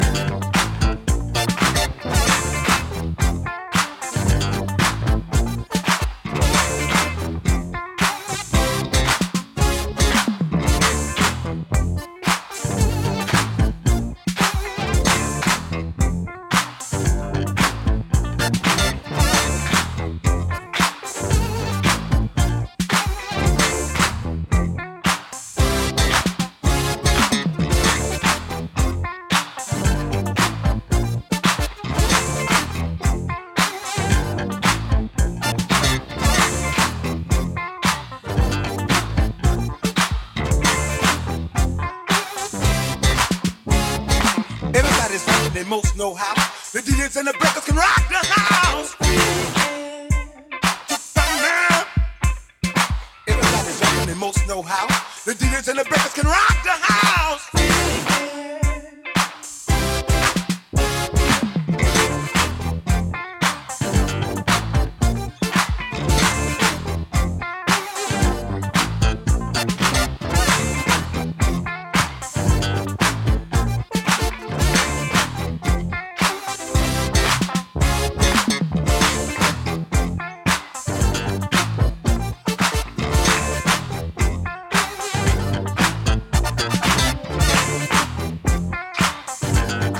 ジャンル(スタイル) BOOGIE / FUNK / SOUL